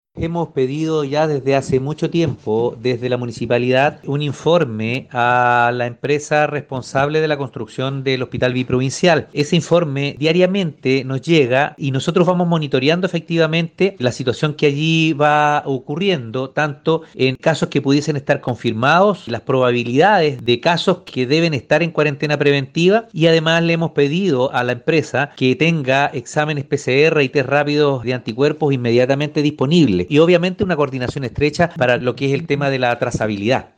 01-ALCALDE-SUBROGANTE-Informes-diarios-Sacyr.mp3